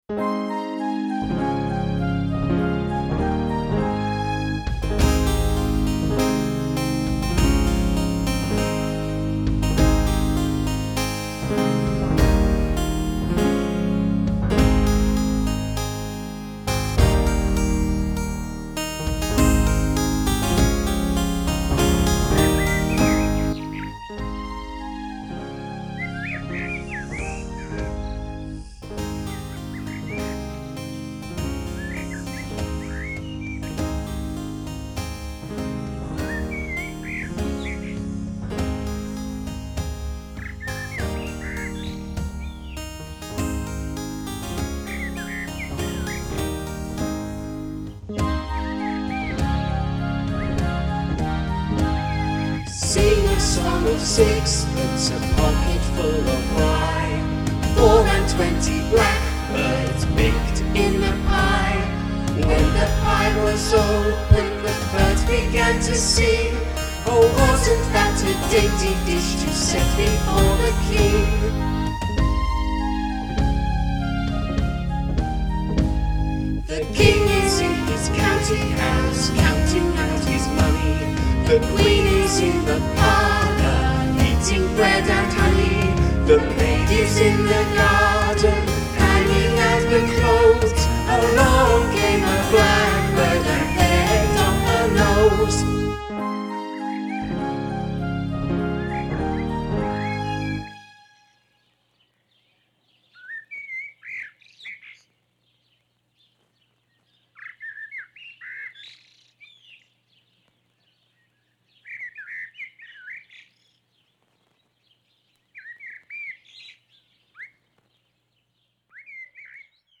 SONGS!!!